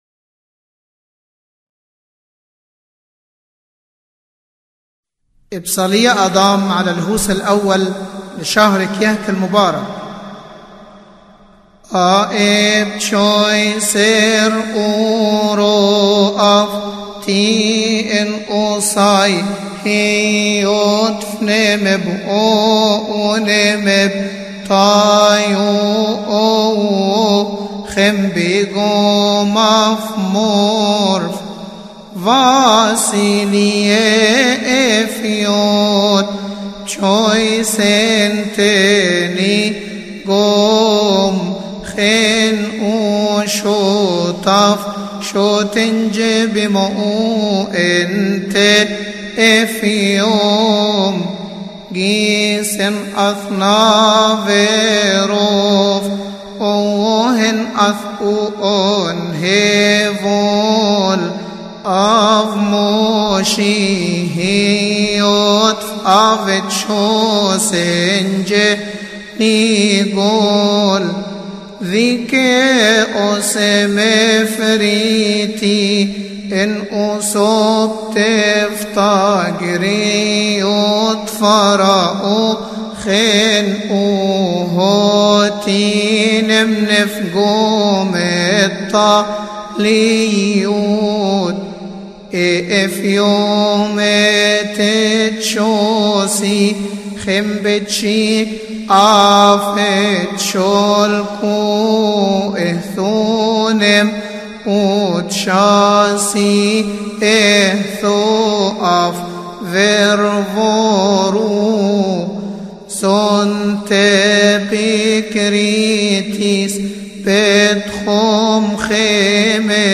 إبصالية تقال علي الهوس الاول في تسبحة نصف الليل بشهر كيهك